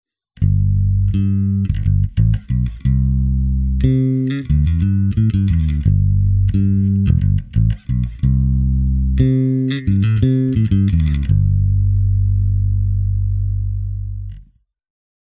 Here are a few sound clips that I’ve recorded with my trusty Jazz Bass, going via my Sans Amp Bass Driver DI into Pro Tools.
My own Jazz Bass is a Japanese Standard-model from 1985, strung with a Rotosoundin Swing Bass -set.
fingerstyle/both PUs
jazz-bass_finger_bothpus.mp3